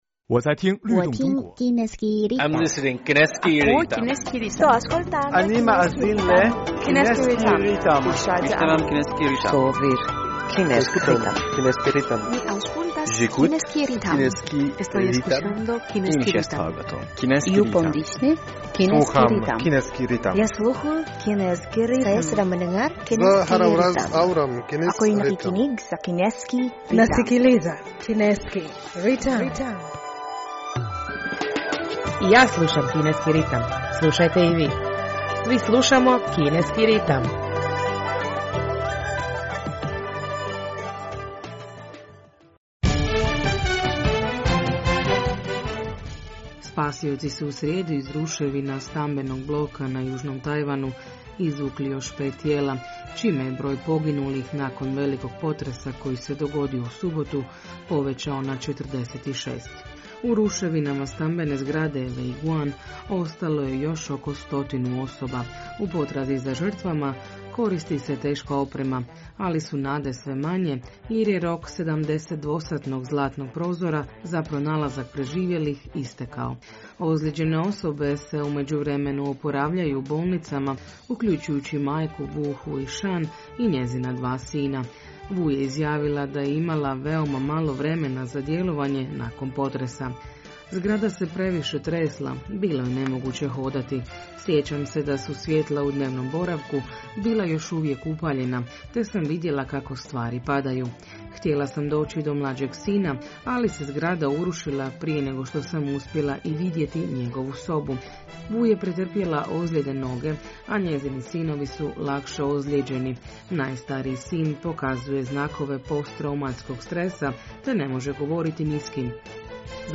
Slušate program na hrvatskom jeziku Kineskog radio Internacionala!